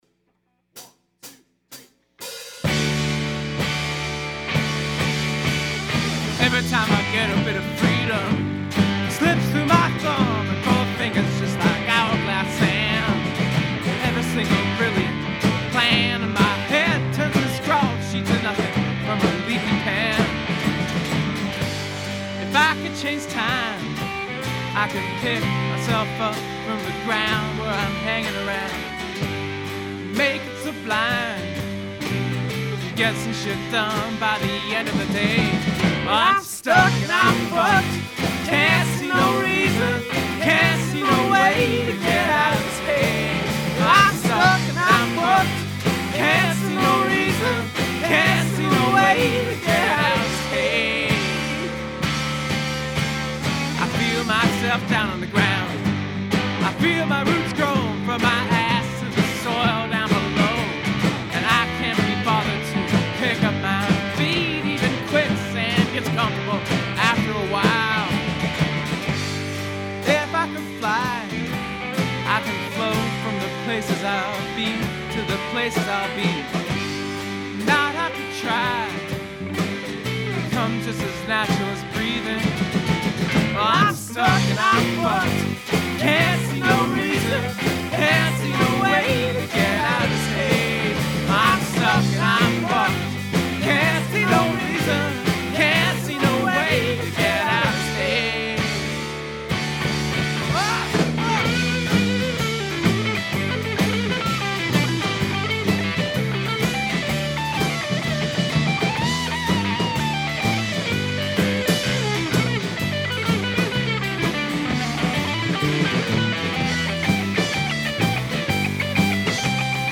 guitar, mandolin
drums